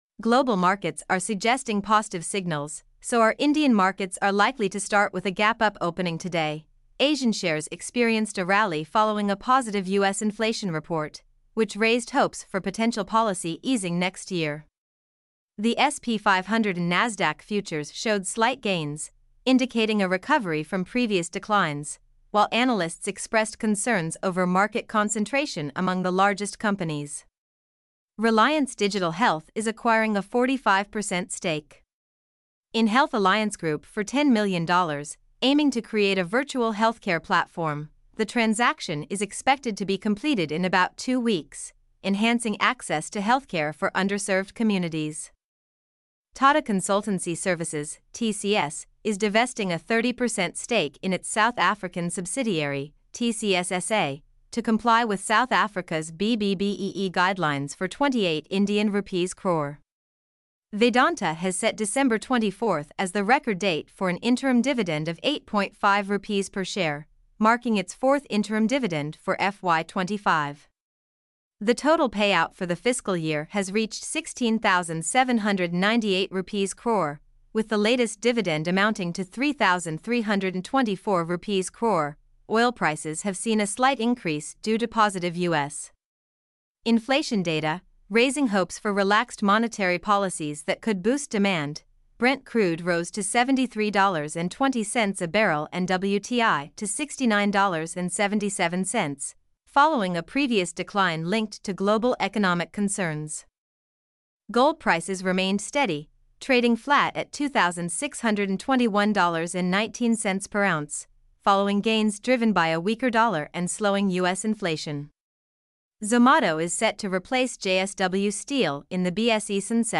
mp3-output-ttsfreedotcom-2.mp3